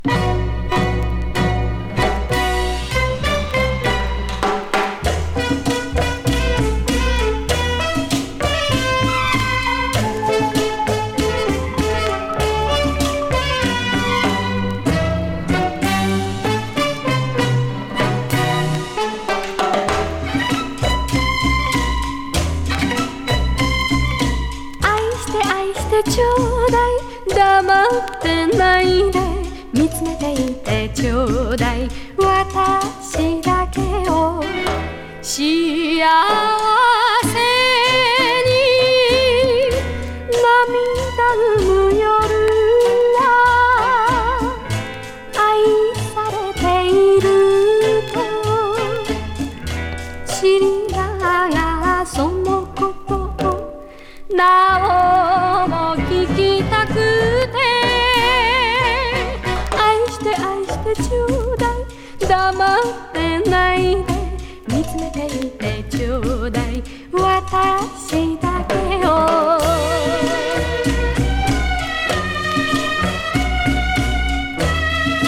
うっすら塗されたラテン風味がニクいタイトル曲ぜひ！
昭和歌謡 レコード